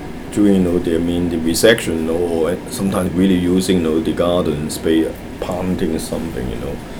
S1 = Taiwanese female S2 = Hong Kong male Context: S2 is talking about recycling, in order to reduce human wastage.
Discussion : There is no [d] at the end of spade ; there is no [l] in planting .